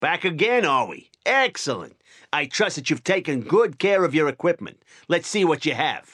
Category:Fallout Tactics audio dialogues Du kannst diese Datei nicht überschreiben.